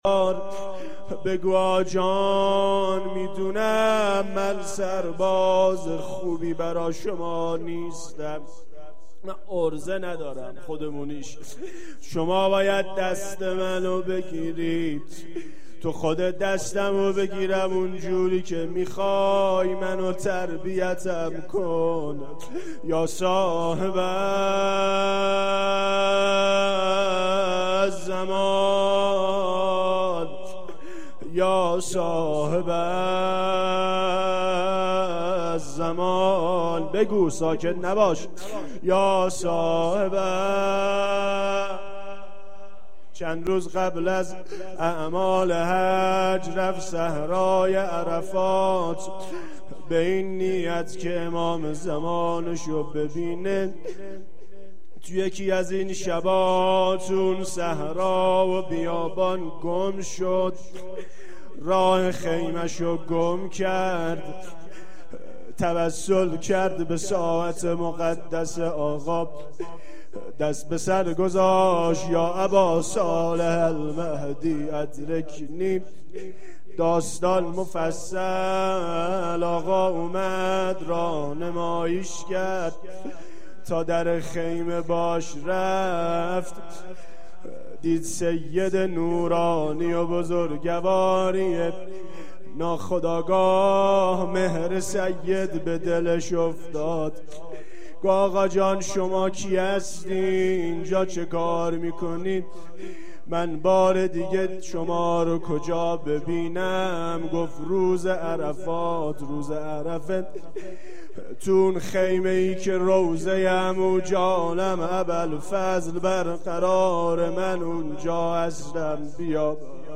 روضه و مناجات
روضه-و-مناجات.mp3